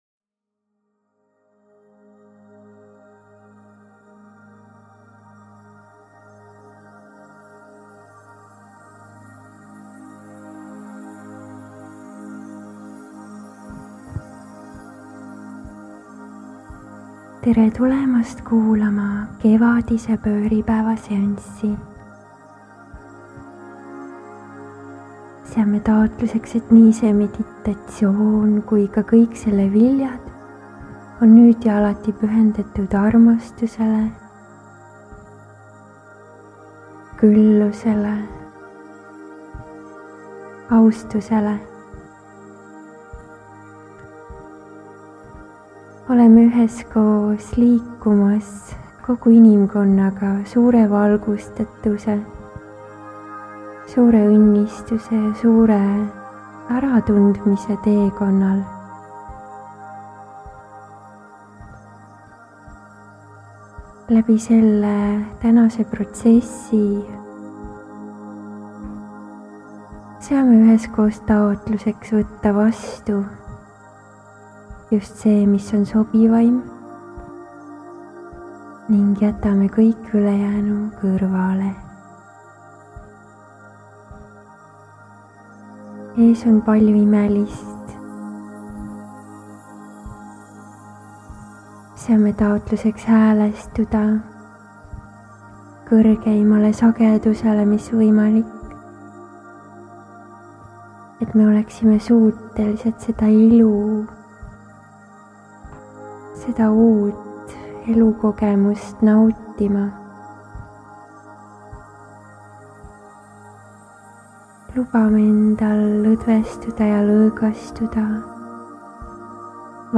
Maagilise Reiki meditatsioon ja korrastus, et sellest saaks sinu elu parim kevad ja nii ka iga järgmine.